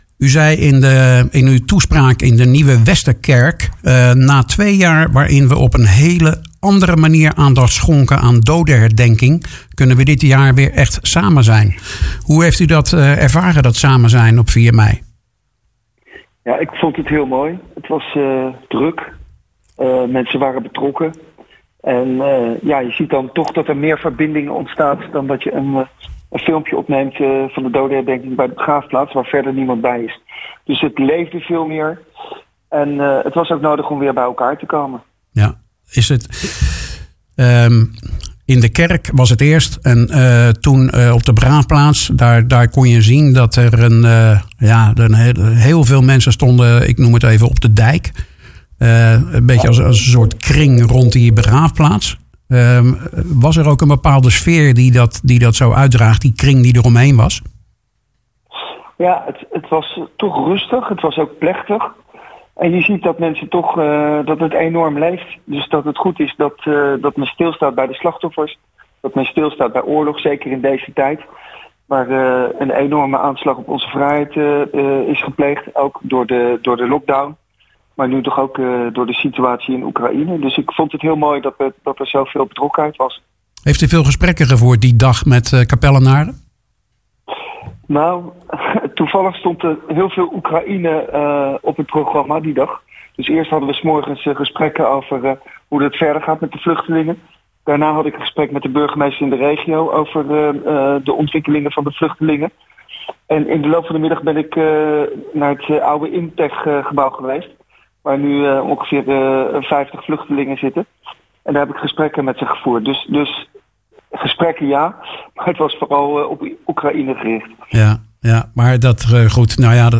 praat met de burgemeester over beide dagen.